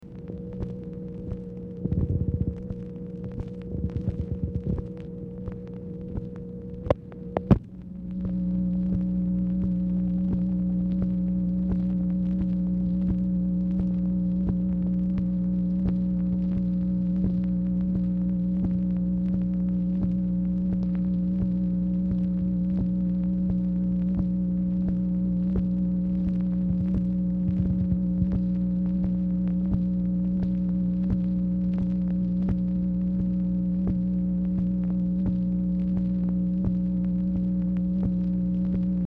Telephone conversation # 10140, sound recording, MACHINE NOISE, 5/25/1966, time unknown | Discover LBJ
Telephone conversation
Format Dictation belt